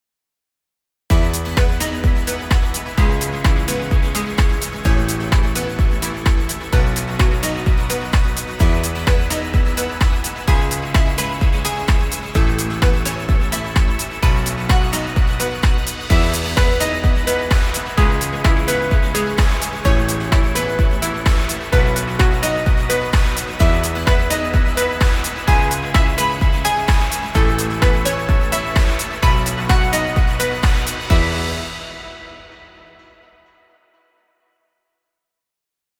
Happy corporate music. Background music Royalty Free.